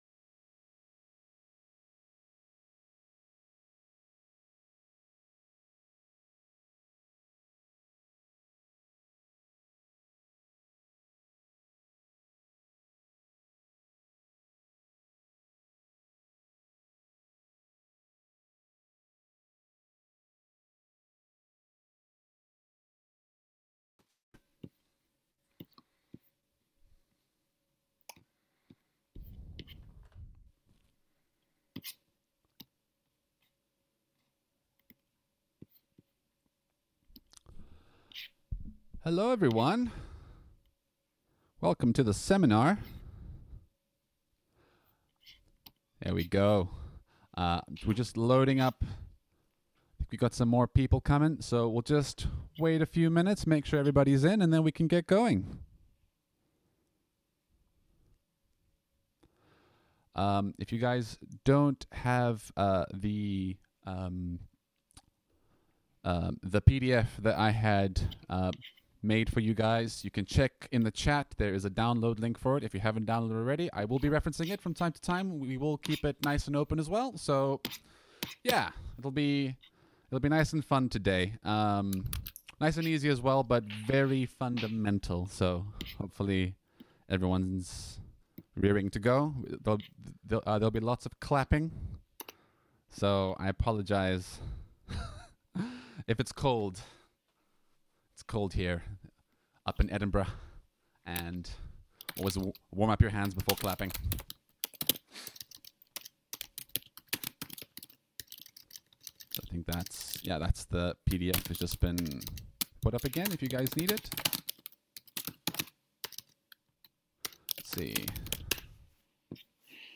Harmonica lessons from extended faculty...
If you've been struggling with rhythm, this is the place to start! We'll be listening to musical examples and clapping along to rhythms in my handout.